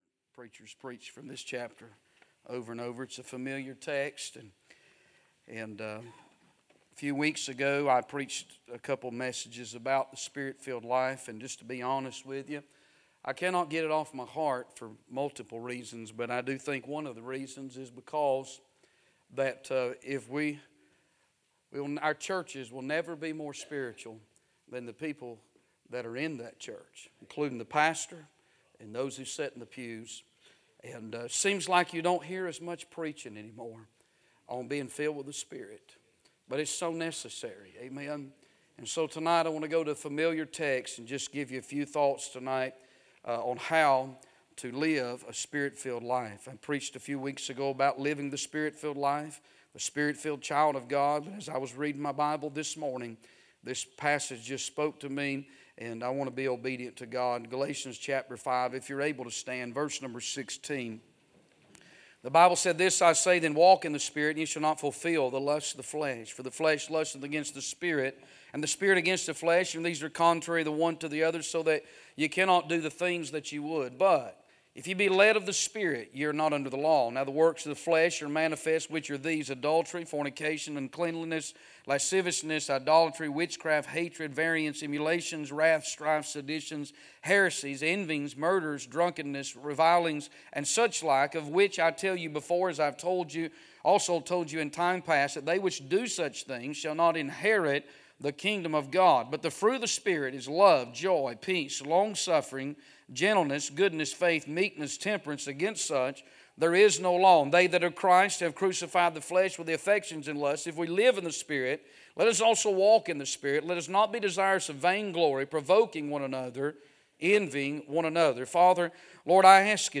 A sermon preached Sunday Evening